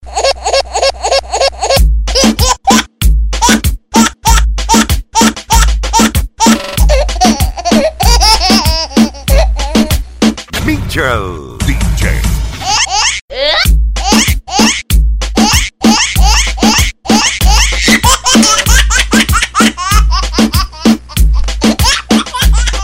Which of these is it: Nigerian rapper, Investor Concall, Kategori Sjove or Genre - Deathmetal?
Kategori Sjove